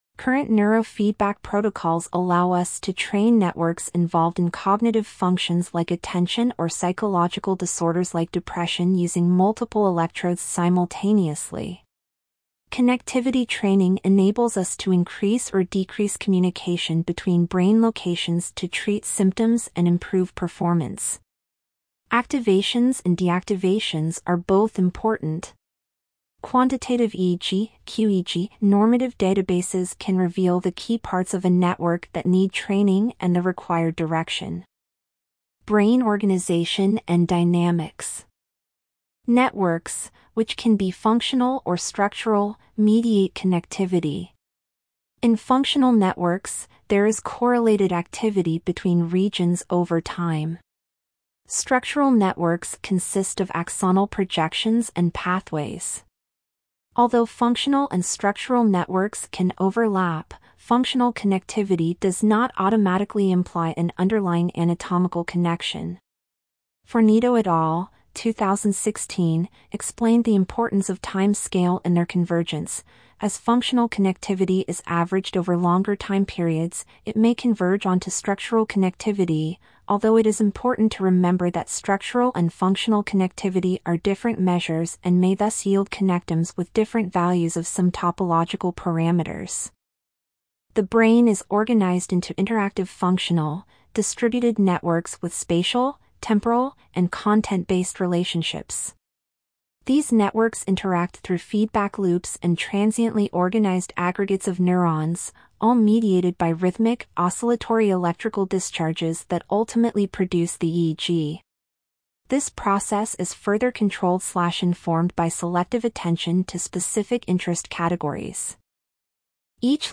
Click on our narrator icon to listen to this post.